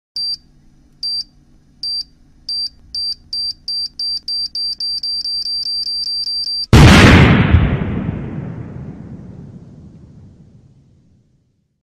دانلود آهنگ ثانیه شمار 10 از افکت صوتی اشیاء
جلوه های صوتی